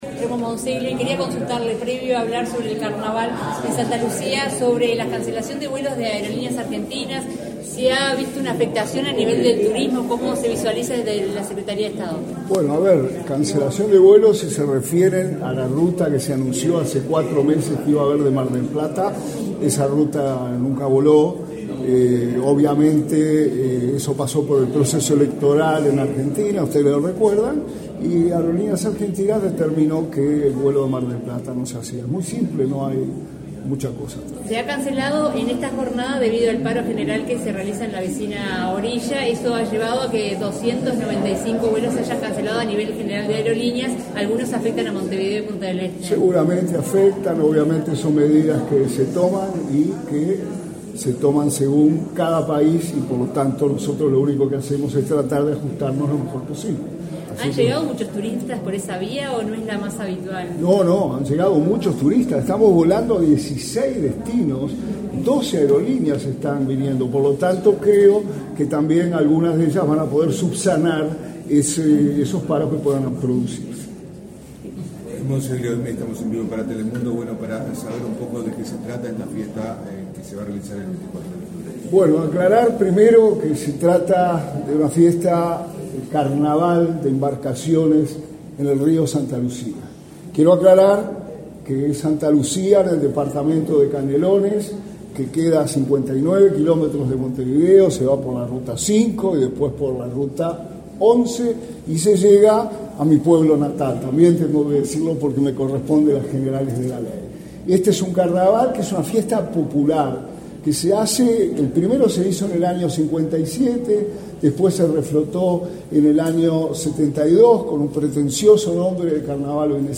Declaraciones a la prensa del ministro interino de Turismo, Remo Monzeglio
Tras participar en el acto de lanzamiento de la octava edición del Carnaval de Embarcaciones del Río Santa Lucía, este 23 de enero, el ministro